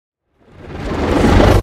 Minecraft Version Minecraft Version 1.21.4 Latest Release | Latest Snapshot 1.21.4 / assets / minecraft / sounds / mob / warden / sonic_charge2.ogg Compare With Compare With Latest Release | Latest Snapshot
sonic_charge2.ogg